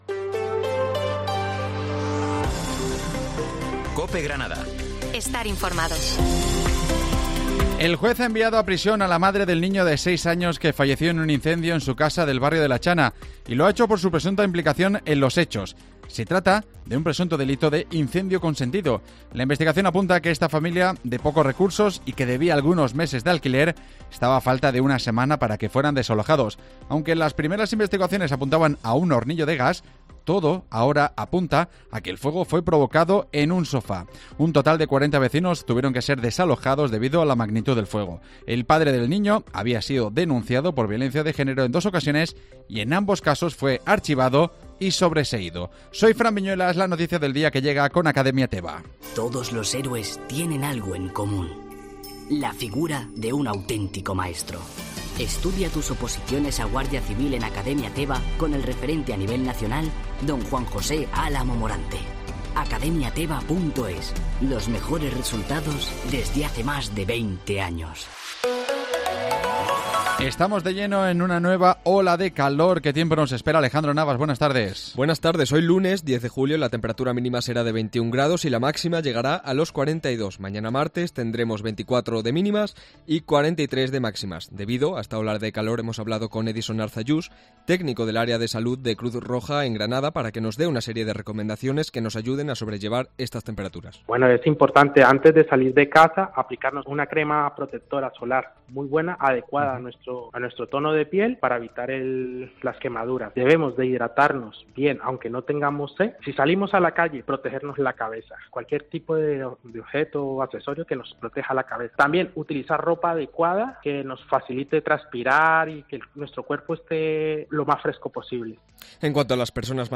Informativo Mediodía Granada - 10 Julio